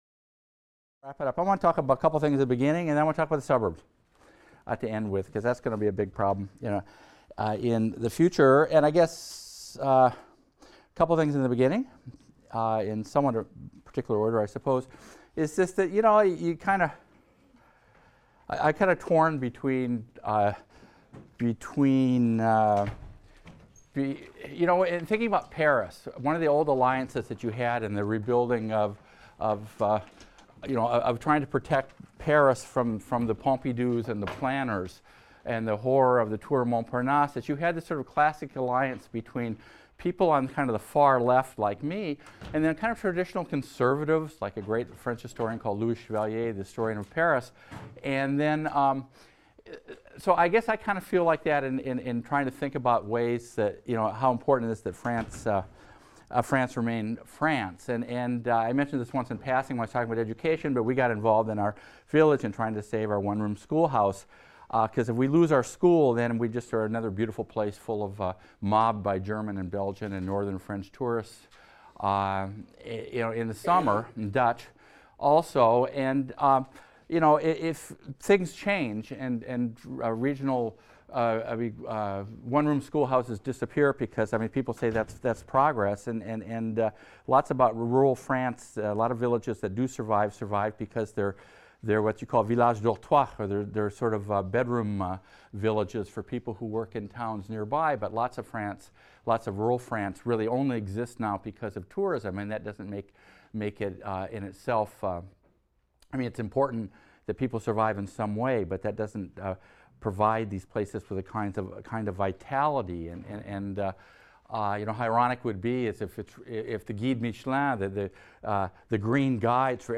HIST 276 - Lecture 24 - Immigration | Open Yale Courses